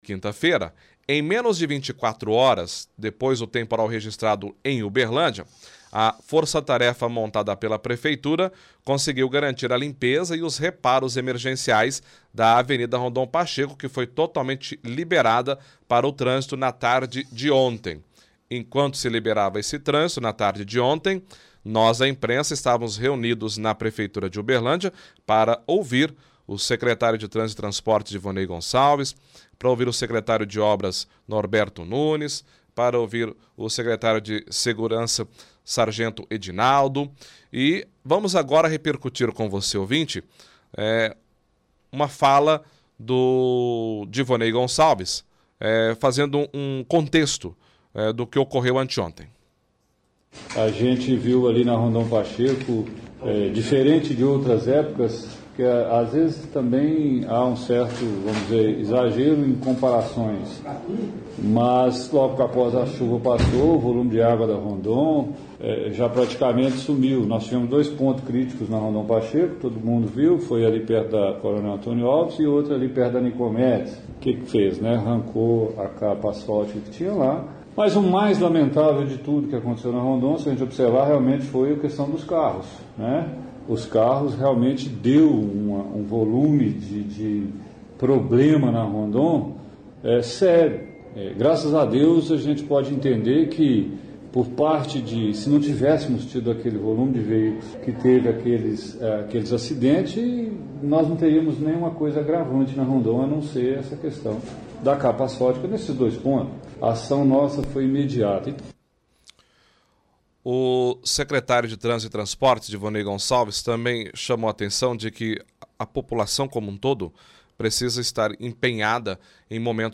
Leitura de release
Exibe trecho de entrevista do secretário de trânsito, Divonei Gonçalves, falando que o mais lamentável foi que haviam muitos carros na Rondon durante a chuva, pedindo para que as pessoas saiam da Rondon durante chuvas.
*Obs.: Ocorreu erro no final da transmissão do programa e faltou o restante da matéria.*